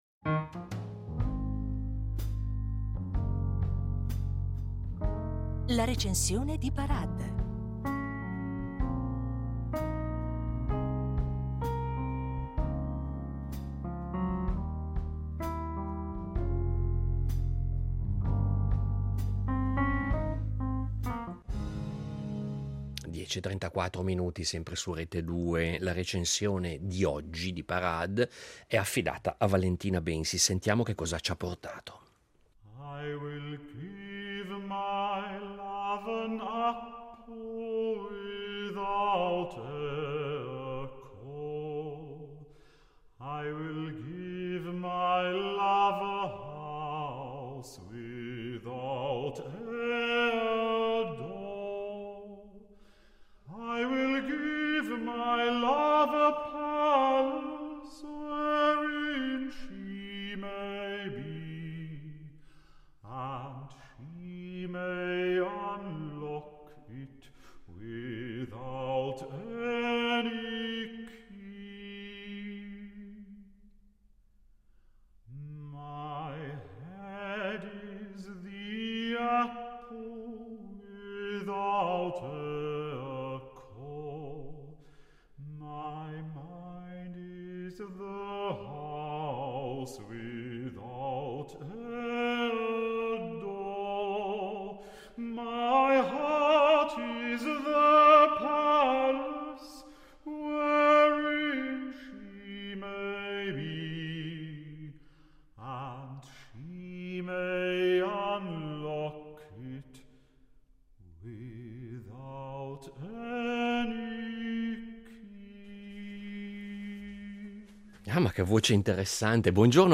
Il tema religioso è però trattato anche in modo scherzoso: a volte Appl pronuncia quelle frasi in modo un po' ironico, e la maggior parte delle proposte musicali racchiudono idee decisamente forti - seduzione, trasgressione, rimpianto. L’ironia è acuta anche nei brani di Francis Poulenc, che evidenziano la dizione francese perfetta del cantante.